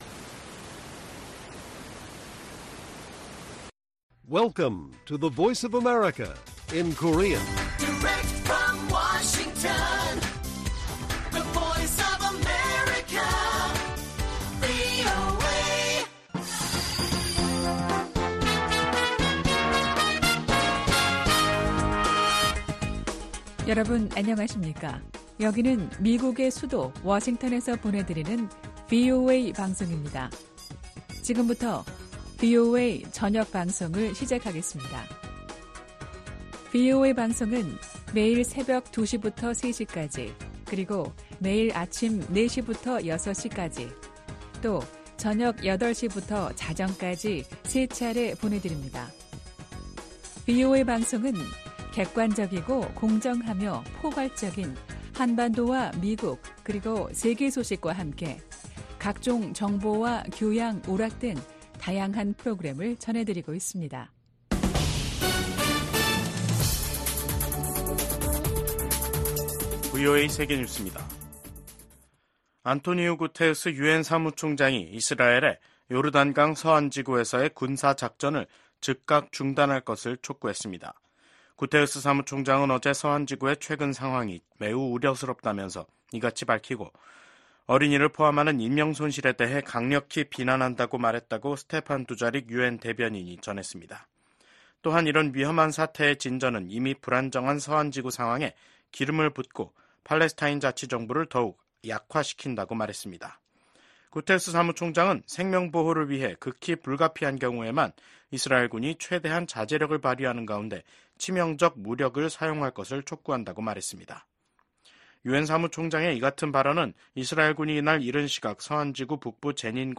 VOA 한국어 간판 뉴스 프로그램 '뉴스 투데이', 2024년 8월 29일 1부 방송입니다. 미국 국무부는 북한의 잠수함 국제해사기구(IMO)에 등록 사실을 인지하고 있다며 북한의 불법 무기프로그램을 규탄한다고 밝혔습니다. 미국 국가안보보좌관이 중국 외교부장과 만나 다양한 현안에 대한 솔직하고 건설적인 대화를 나눴다고 백악관이 밝혔습니다. 윤석열 한국 대통령은 29일 미한일 정상의 캠프 데이비드 협력은 지도자가 바뀌더라도 변하지 않을 것이라고 밝혔습니다.